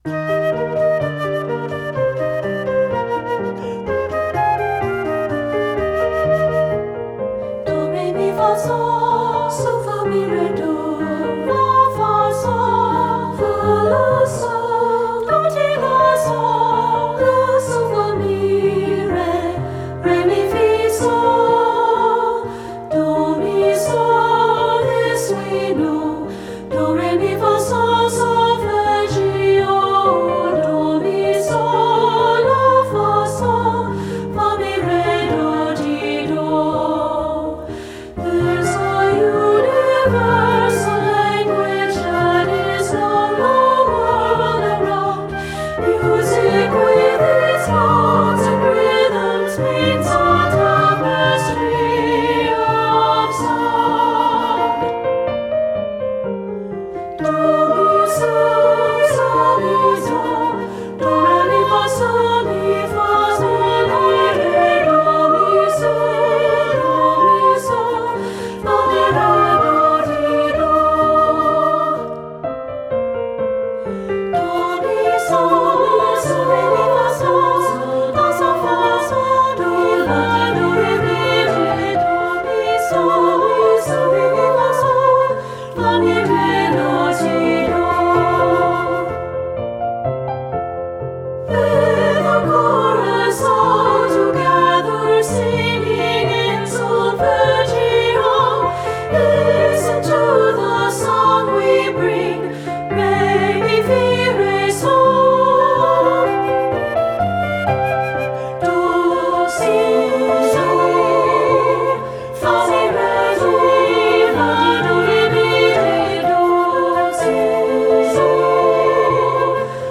• Flute
• Piano
Studio Recording
Ensemble: Treble Chorus
Key: E major
Tempo: Moderato (q = 120)
Accompanied: Accompanied Chorus